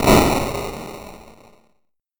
explosion.ogg